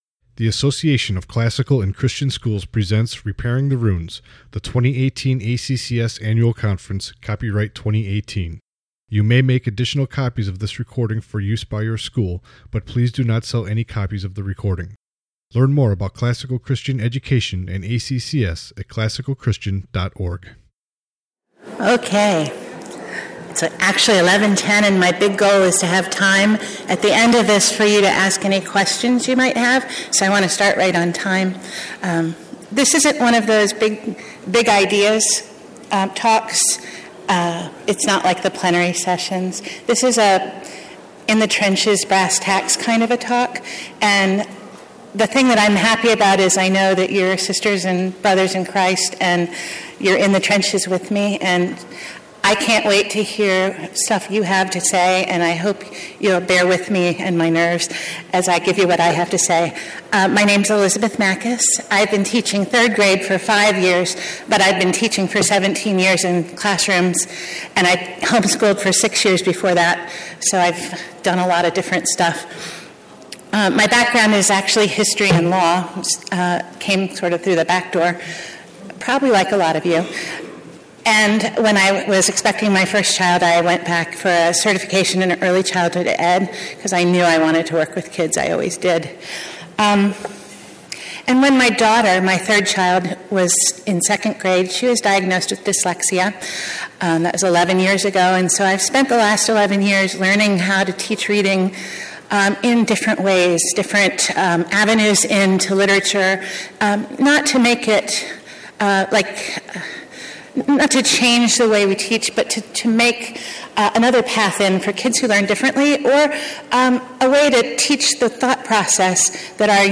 2018 Workshop Talk | 58:26 | K-6, Literature